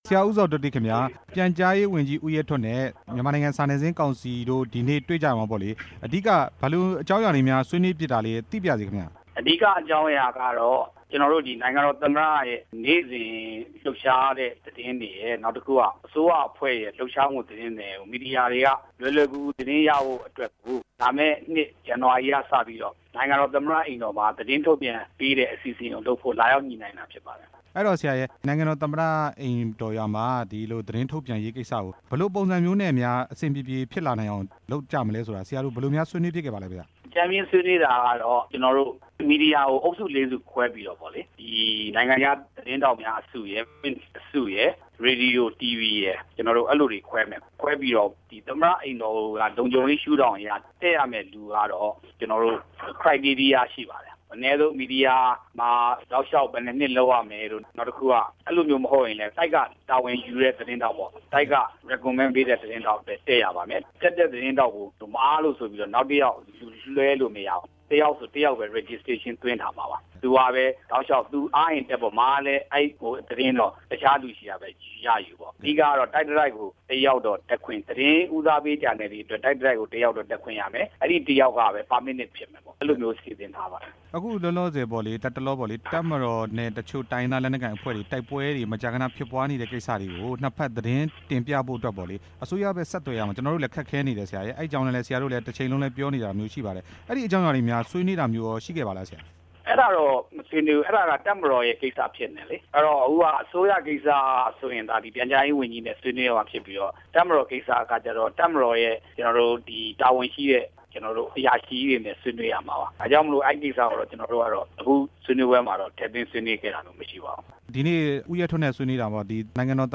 နိုင်ငံတော်သမ္မတ အိမ်တော်ကနေ သတင်းယူခွင့်ပြုလိုက်တဲ့အကြောင်း မေးမြန်းချက်